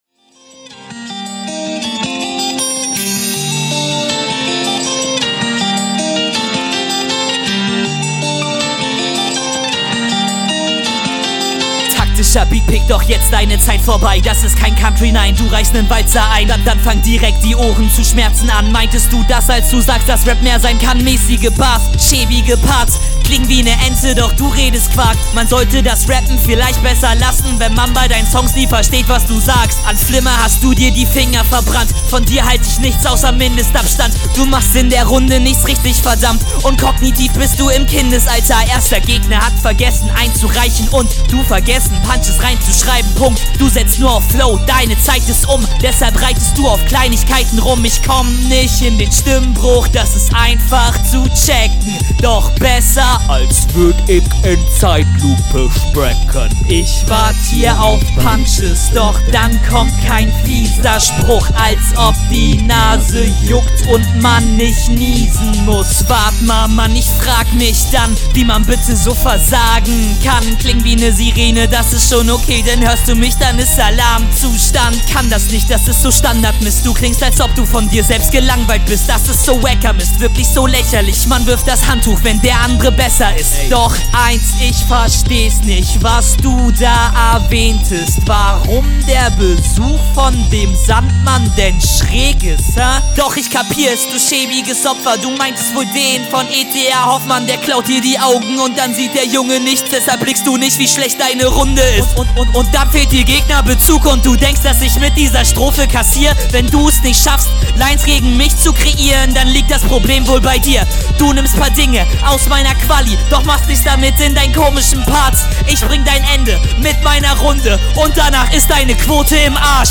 Generell bist du ein Character, Delivery alle 3 Runden super gut, sammle noch …